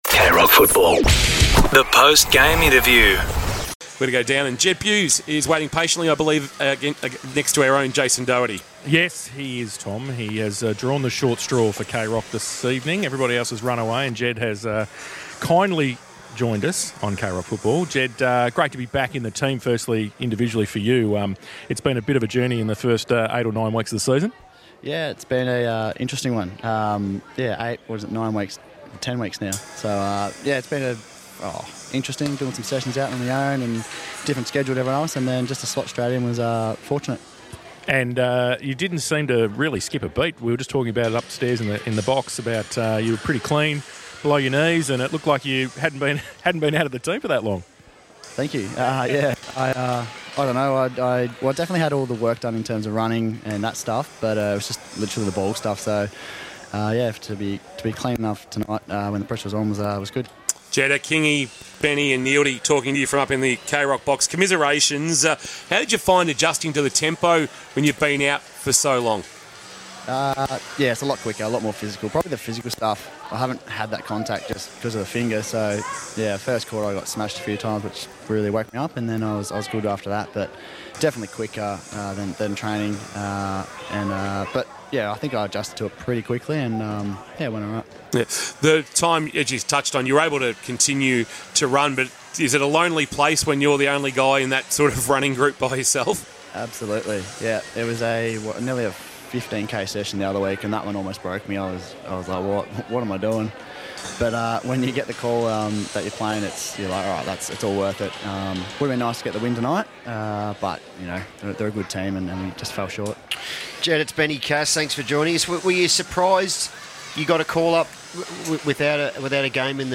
2025 - AFL - Round 9 - Geelong vs. GWS: Post-match interview - Jed Bews (Geelong)